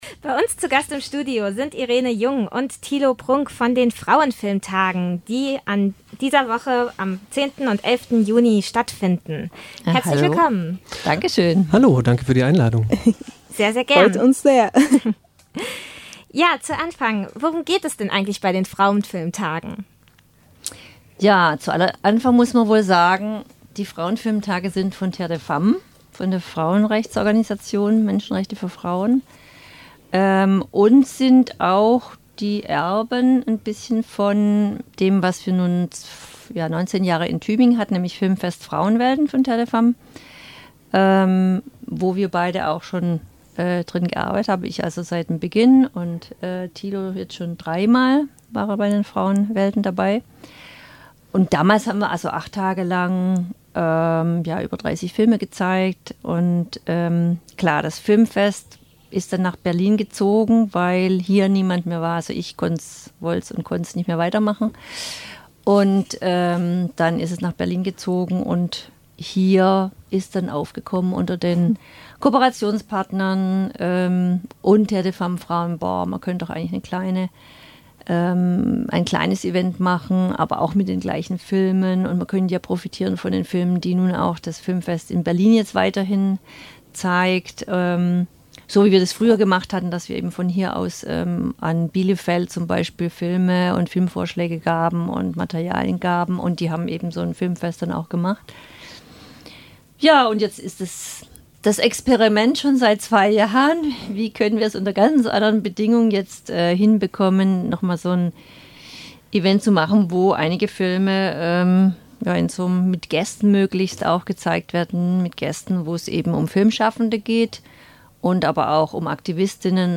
81321_Interview_Film_Festivel_lang.mp3